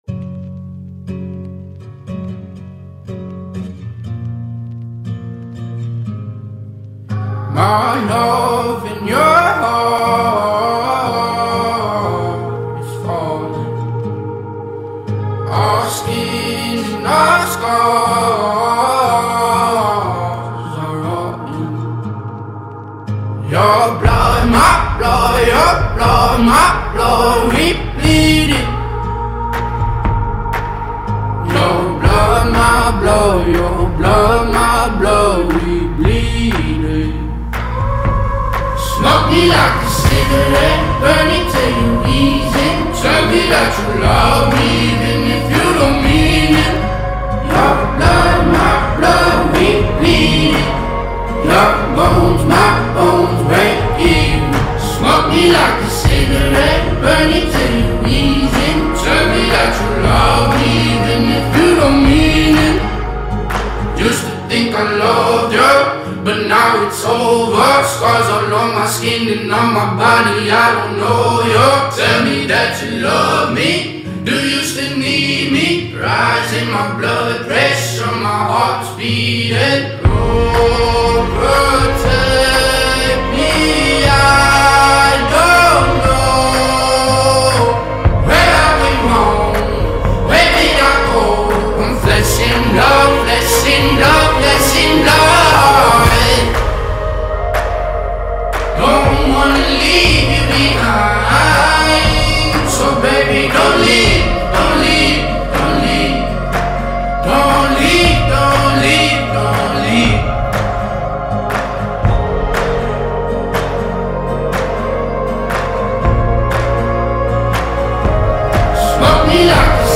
ملودی غمگین